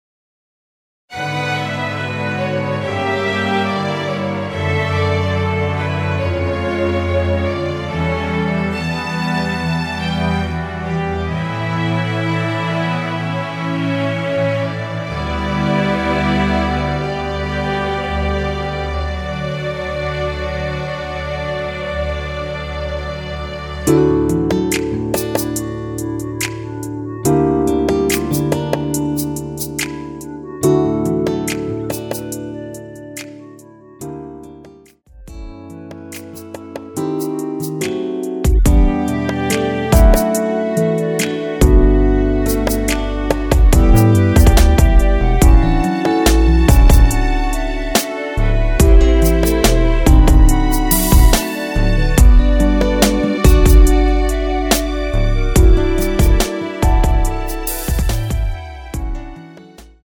원키에서(-2)내린 멜로디 포함된 MR입니다.
주 멜로디만 제작되어 있으며 화음 라인 멜로디는 포함되어 있지 않습니다.(미리듣기 참조)
앞부분30초, 뒷부분30초씩 편집해서 올려 드리고 있습니다.
중간에 음이 끈어지고 다시 나오는 이유는